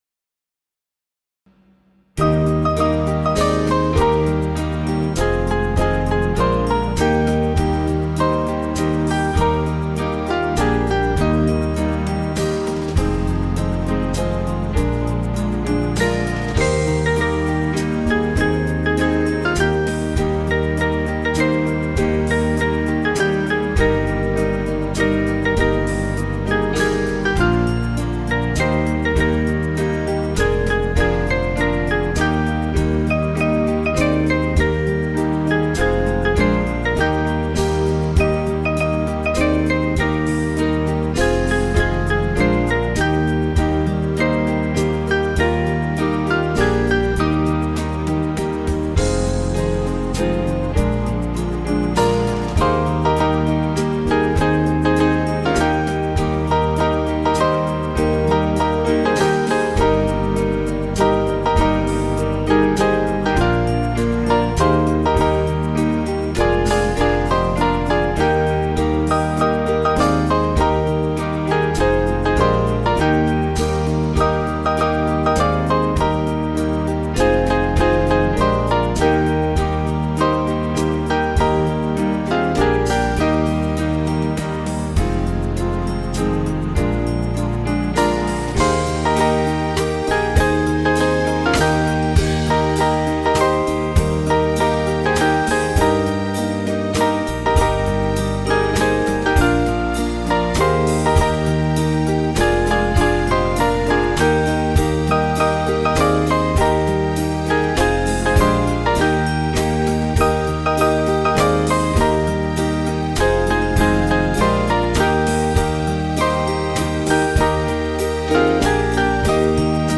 Small Band
(CM)   3/Eb 479.5kb